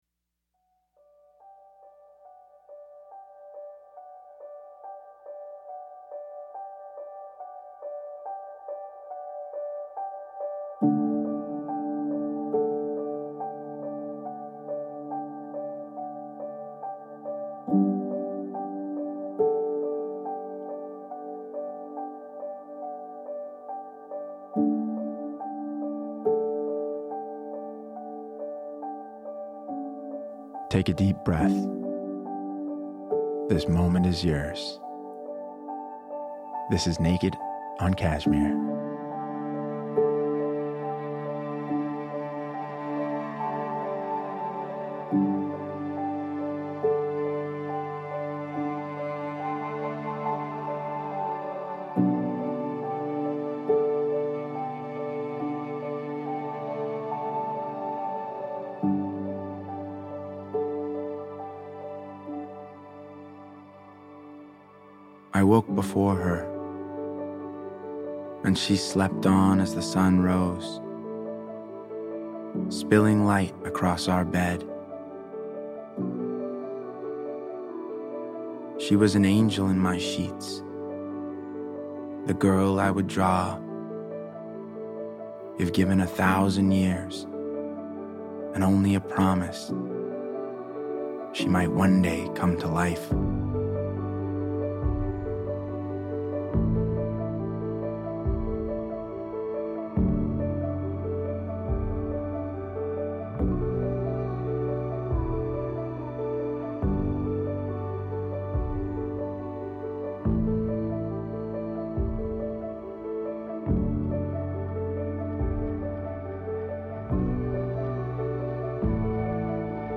Atticus presents a daily poetry reading